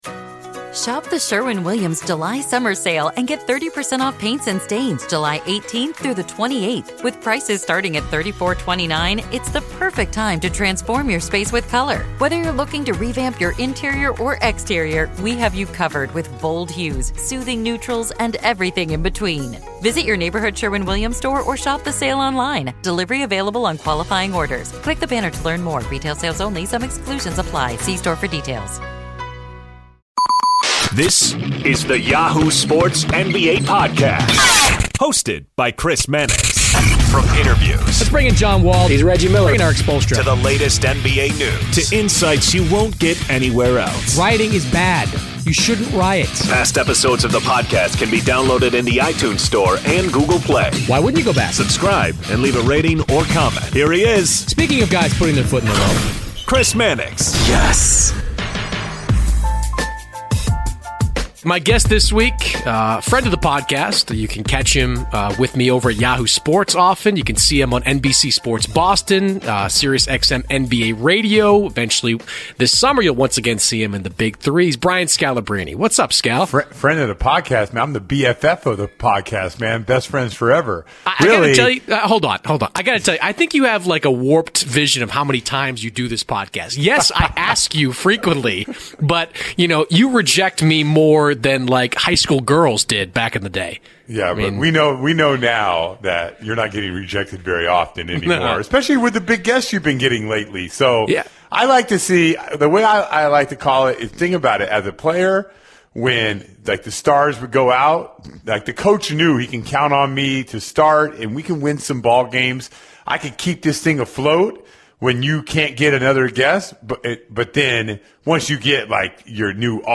Brian Scalabrine and CJ McCollum The Crossover NBA Show SI NBA Basketball, Sports 4.6 • 641 Ratings 🗓 20 March 2018 ⏱ 67 minutes 🔗 Recording | iTunes | RSS 🧾 Download transcript Summary Joining Chris Mannix of Yahoo Sports this week are two guests: Former NBA forward Brian Scalabrine on Ty Lue’s health problems, Portland’s hot play, Utah’s second half surge, Boston’s injuries and more.